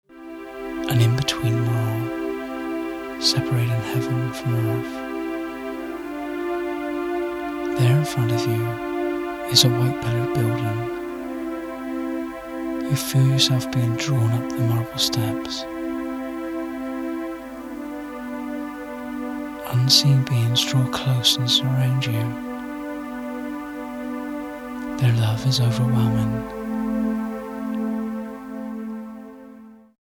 The Healing Angel guided meditation has been created for those of you with a love for the angels. It will take you on a journey to meet these angelic beings, where you will receive healing for your mind, body & spirit.